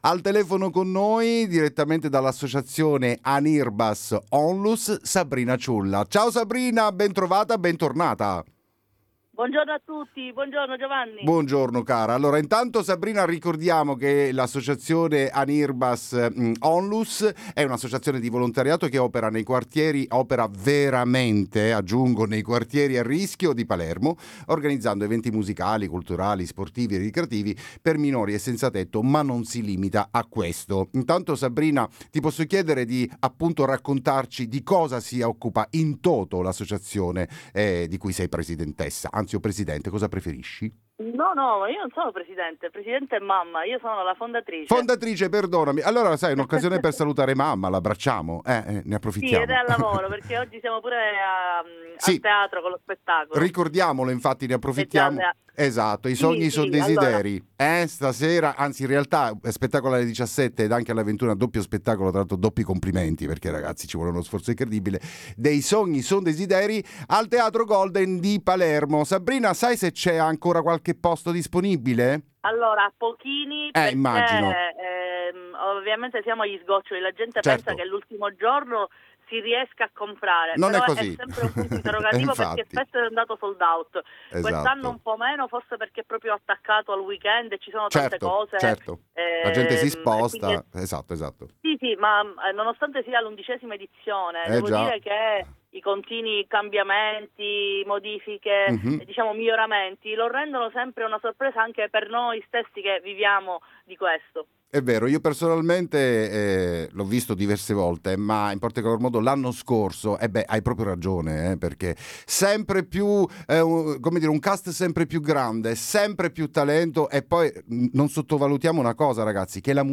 Buongiorno da Marino Interviste